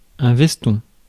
Ääntäminen
Synonyymit chpens Ääntäminen France: IPA: [vɛs.tɔ̃] Haettu sana löytyi näillä lähdekielillä: ranska Käännös Ääninäyte Substantiivit 1. jacket GenAm RP US 2. blazer 3. suit jacket Suku: m .